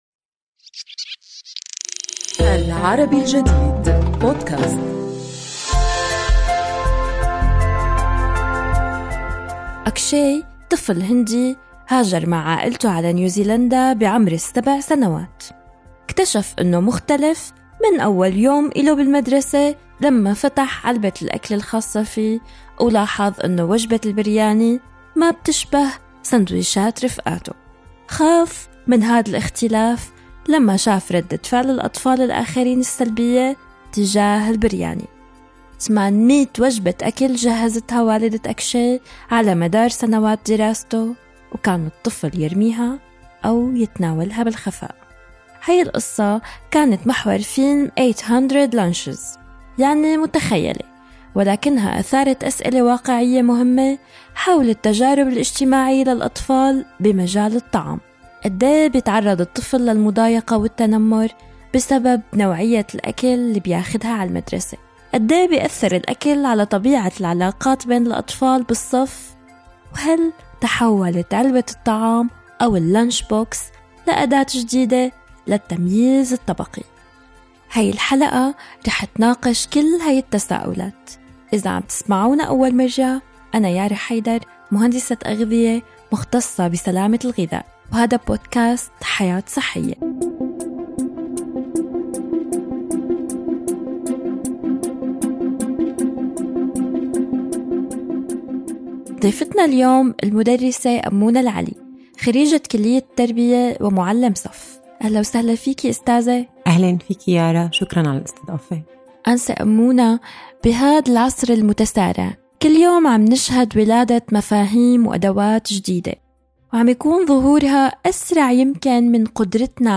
في هذه الحلقة من بودكاست "حياة صحية"، نستضيف المعلمة